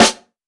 Dilla Snare 21.wav